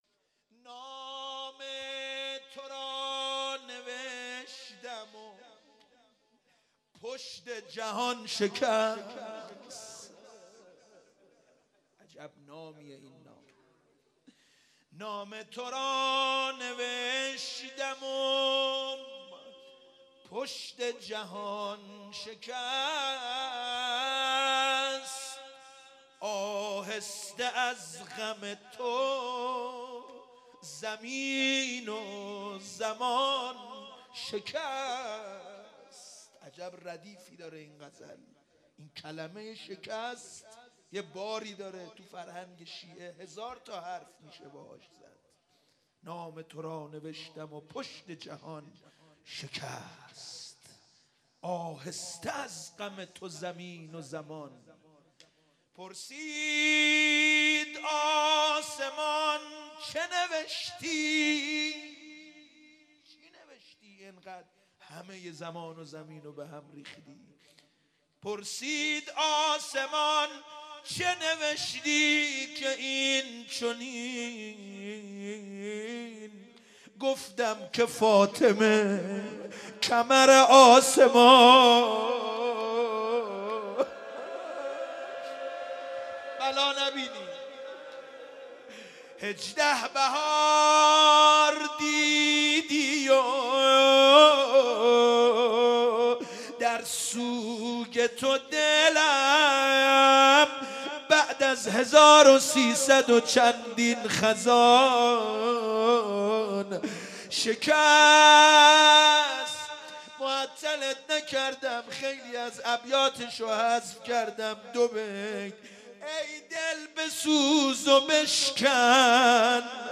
شب دوم_روضه_حضرت زهرا سلام الله علیها